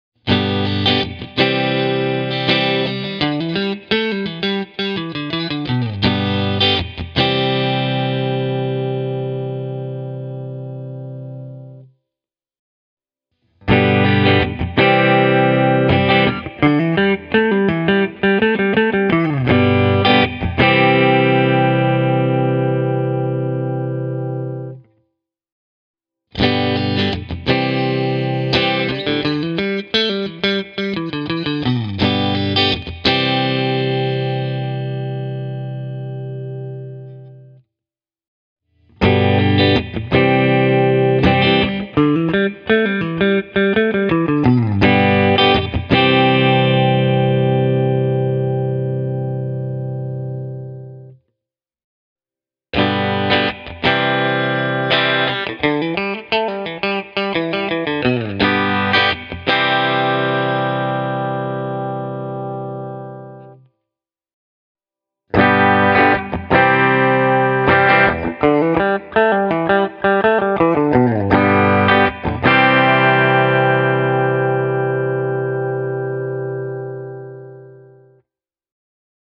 Lisäksi humbuckerien puolitus yksikelaisiksi lisää tutuille humbucker-soundeille vielä hyvin hyödylliset kevyemmät ja kirkkaammat vaihtoehdot.
Esimerkkipätkissä aloitan aina puolitetusta kaulamikrofonista, sen jälkeen tulee täysi kaulamikki ja niin edelleen:
ESP Eclipse II Distressed – puhtaat
esp-eclipse-ii-distressed-e28093-clean.mp3